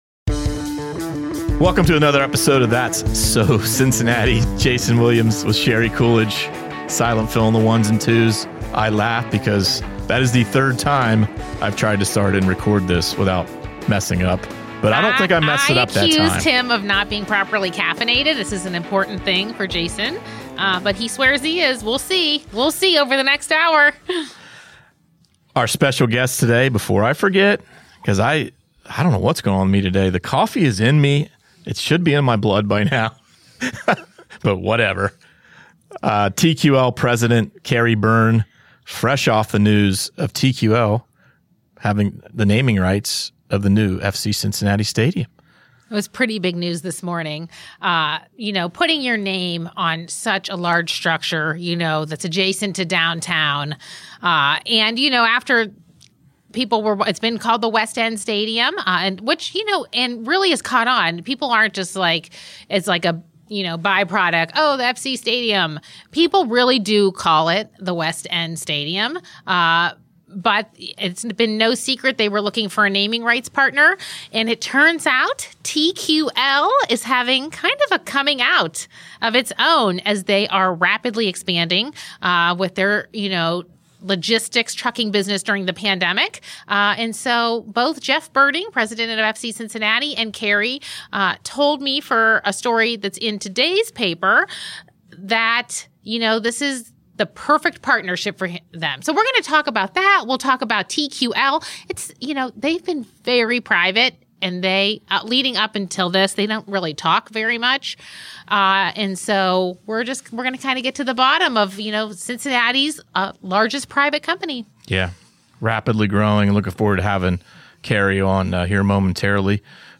In an interview with The Enquirer's That's So Cincinnati podcast